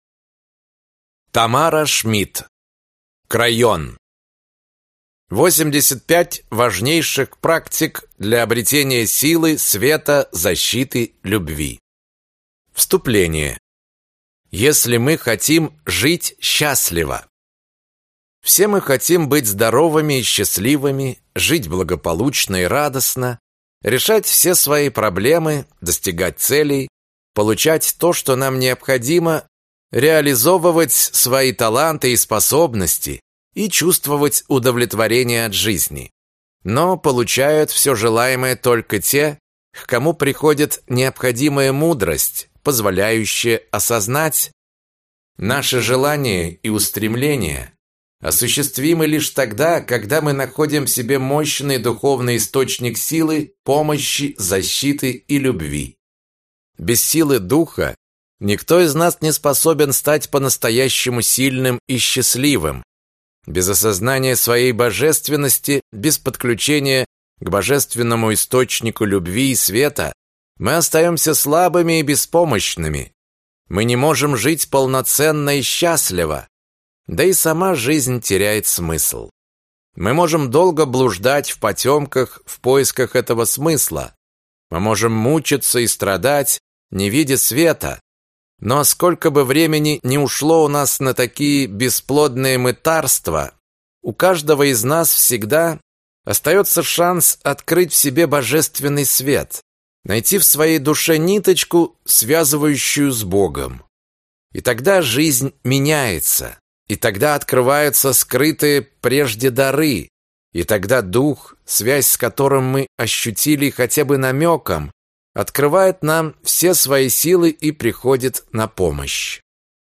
Аудиокнига Крайон. 85 важнейших практик для обретения Силы, Света, Защиты и Любви | Библиотека аудиокниг